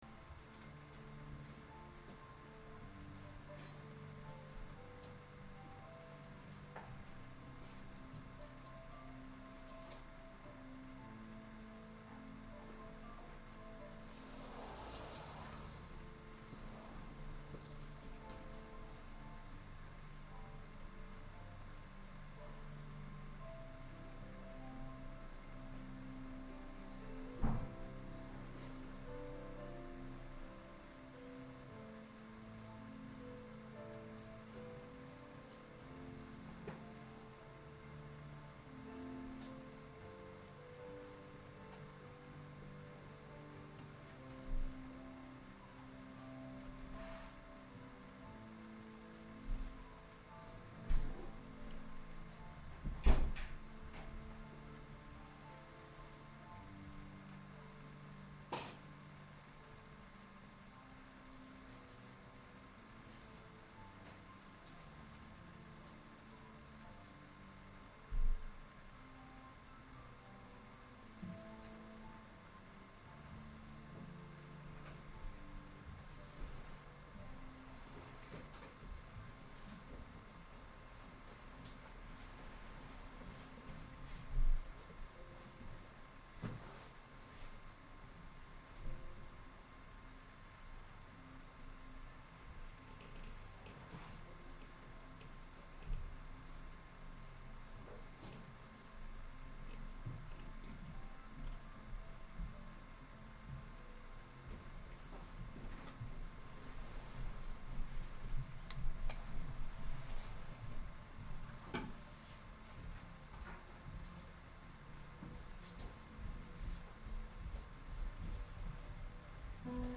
Sermons Series